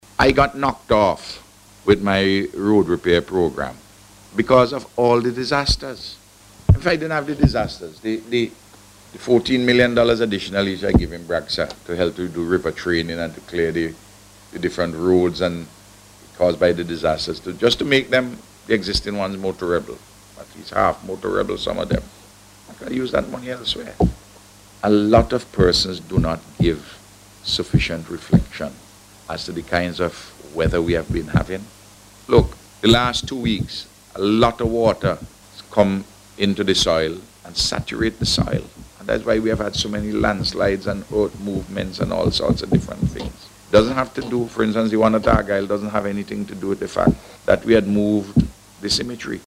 The Prime Minister made the point, as he responded to a question from the media, at a News Conference on Tuesday.